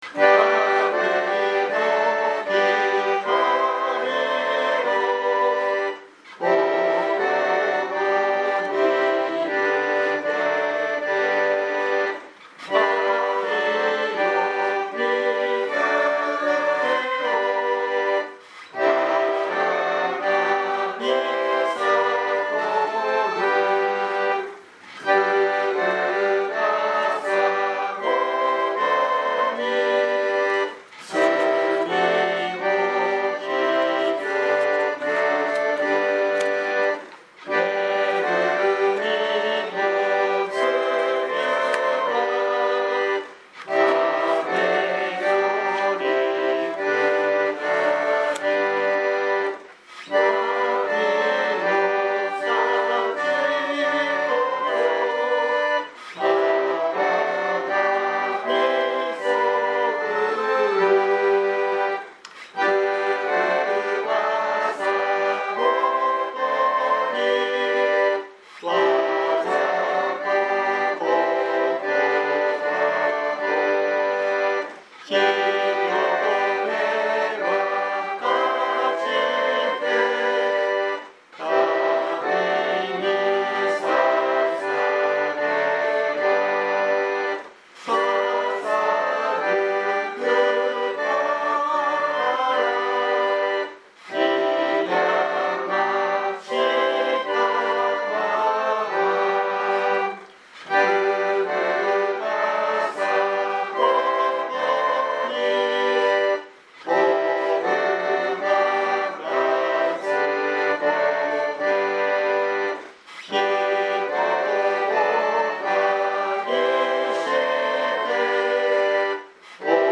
2023年07月02日朝の礼拝「心を騒がせるイエス」熊本教会
熊本教会。説教アーカイブ。